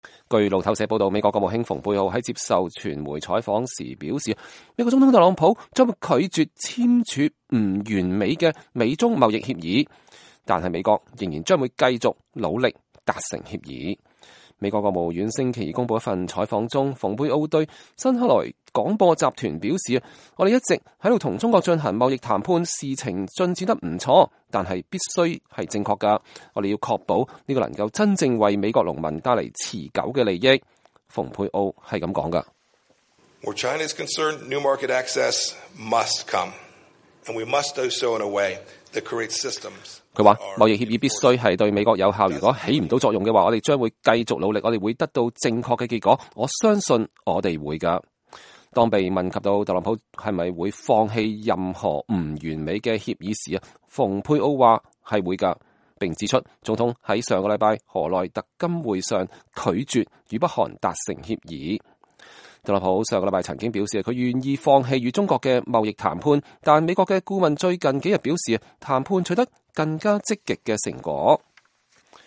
美國國務卿蓬佩奧在愛奧華州向美國未來農場主協會成員和約翰斯頓高中學生髮表講話。(2019年3月4日)